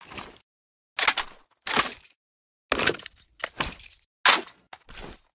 m60_reload2.ogg